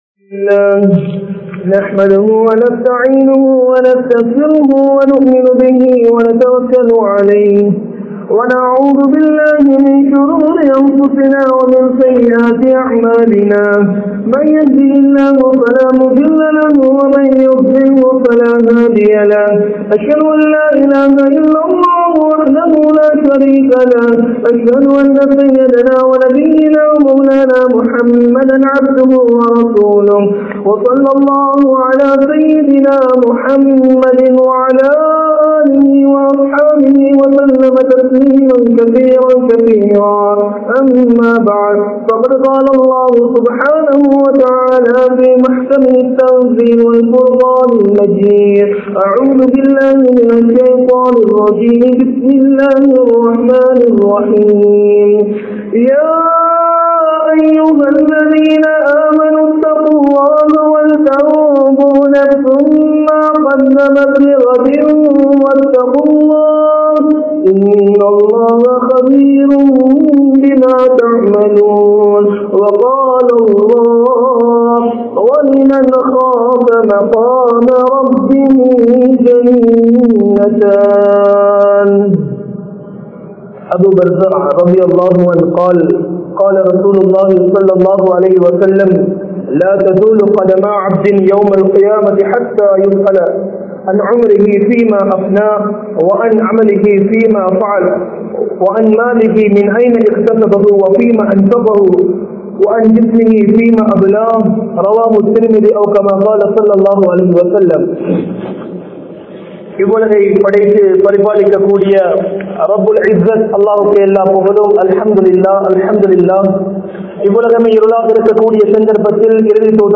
Vaqf Soththukkalai Paathuhaarungal (வக்ப் சொத்துக்களை பாதுகாருங்கள்) | Audio Bayans | All Ceylon Muslim Youth Community | Addalaichenai
Rathnapura, Jannath Jumua Masjith